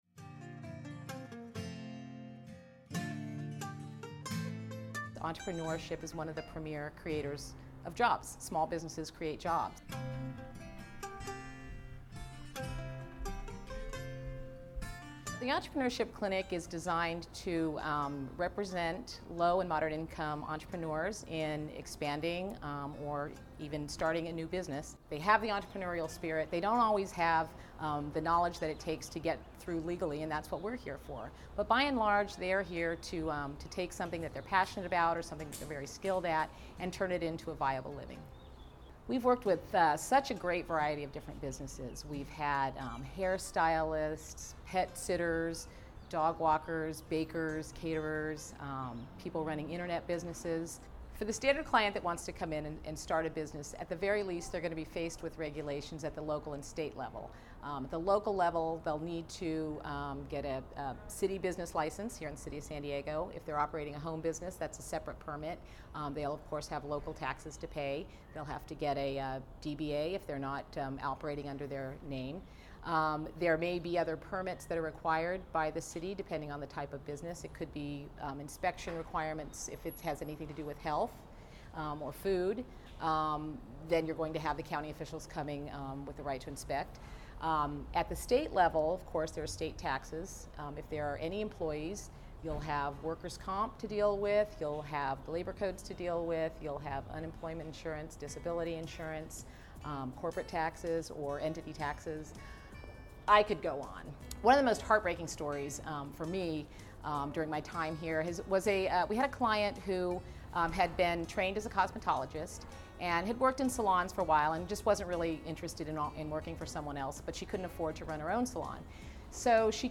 Power to the Entrepreneurs: A conversation